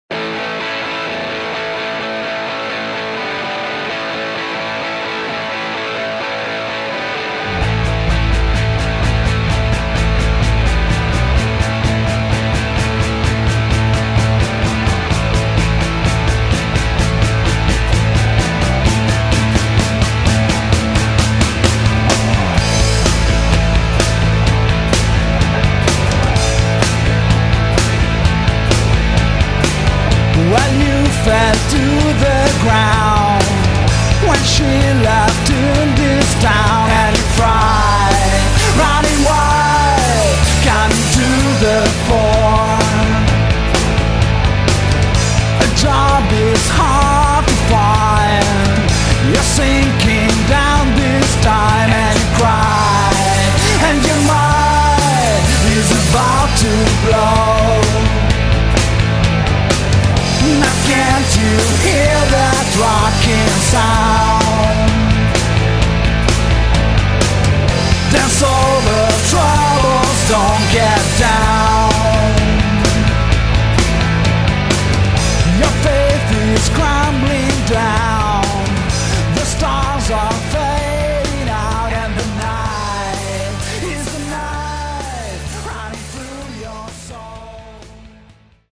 una bomba di elettricità